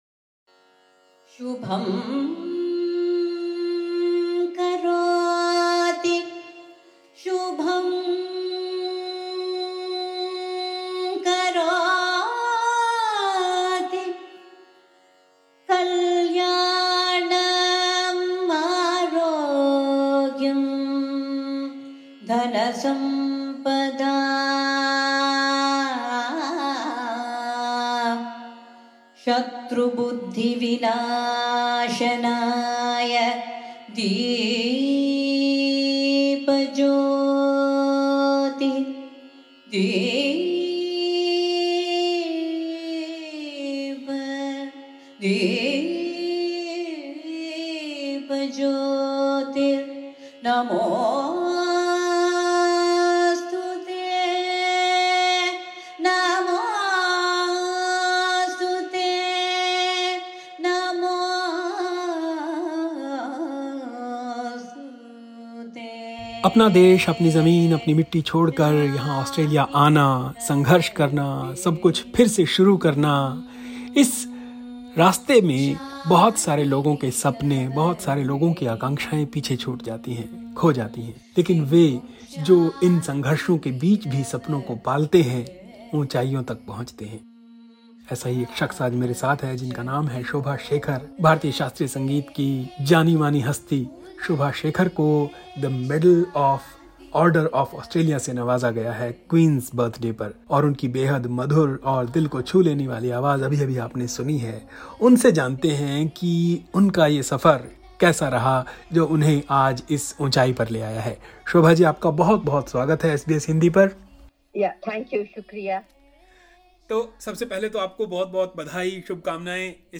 Listen to this inspiring conversation, here: